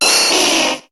Cri d'Herbizarre dans Pokémon HOME.